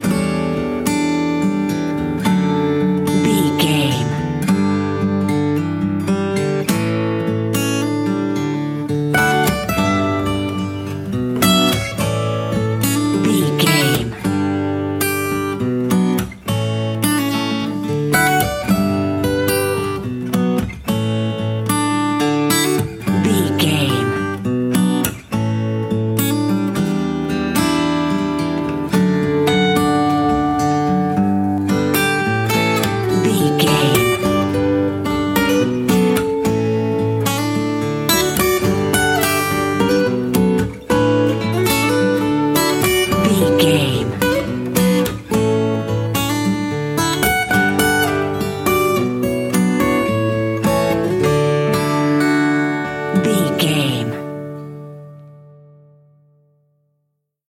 Ionian/Major
acoustic guitar